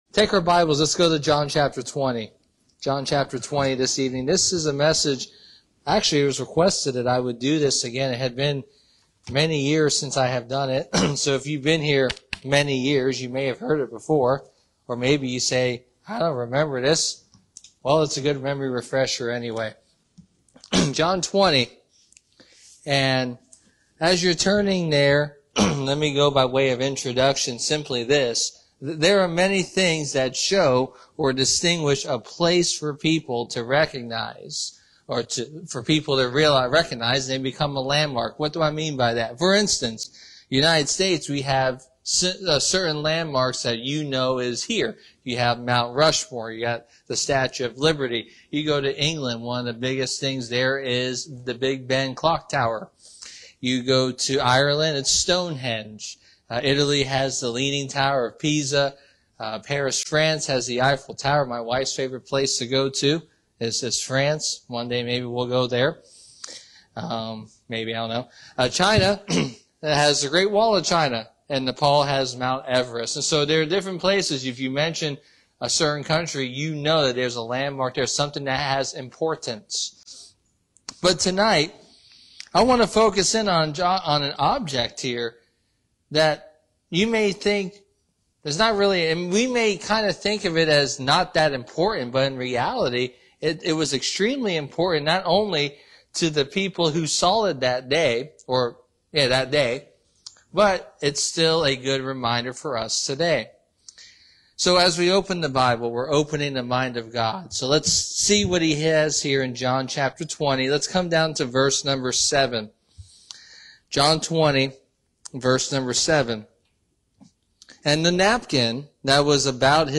“I’m Not Finished Yet” sermon teaches us that a simple folded napkin in Jesus’ tomb holds is a signal for believers to share the Gospel.